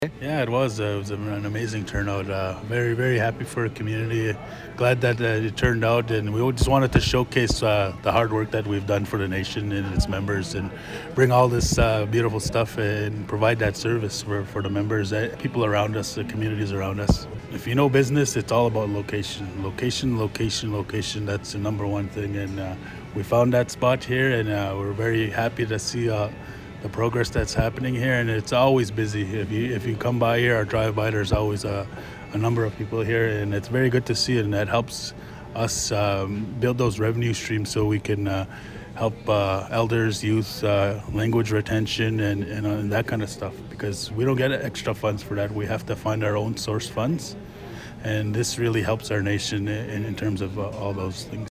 With hundreds gathering on the opening day, Chief Trevor John spoke to CFWE about how pleased he was to see all the smiling faces. He took some time to mention the location, saying this will generate lots of revenue to ensure their community can preserve longstanding cultural traditions.